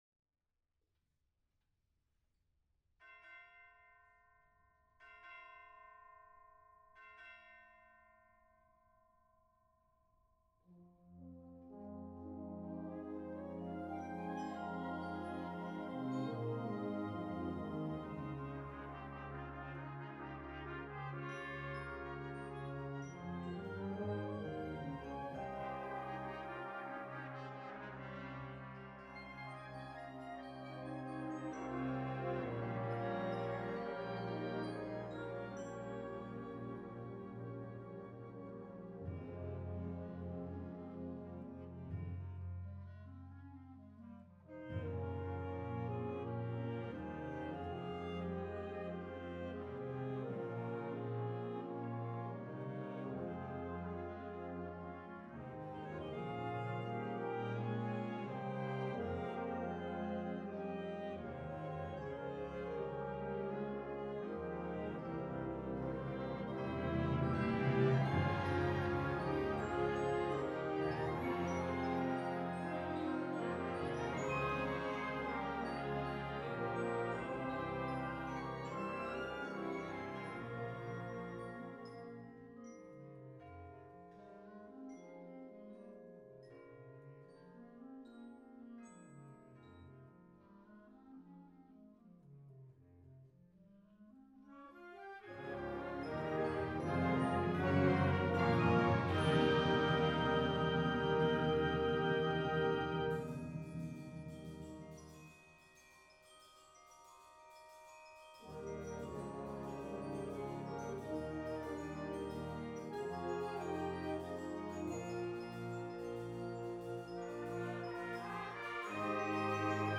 Genre: Band
Timpani
Percussion 2 (Vibraphone)
Percussion 3 (Chimes)